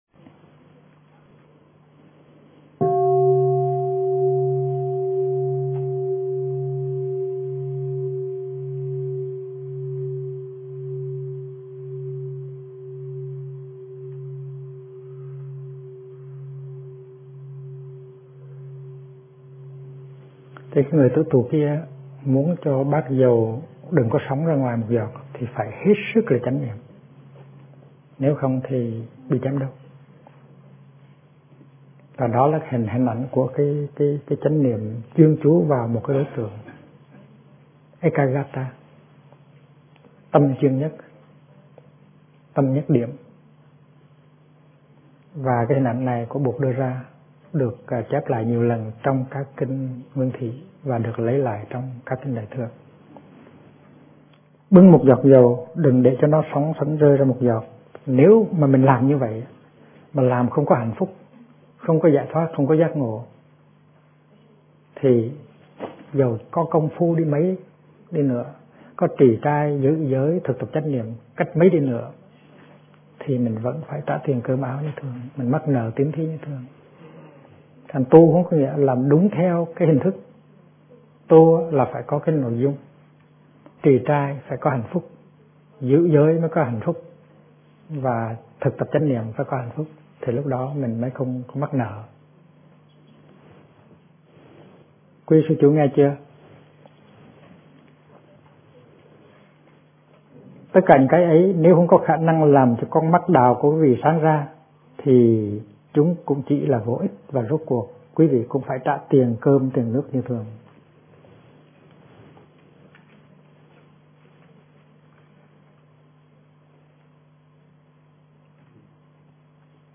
Kinh Giảng Bút Pháp Và Đạo Là Thật - Thích Nhất Hạnh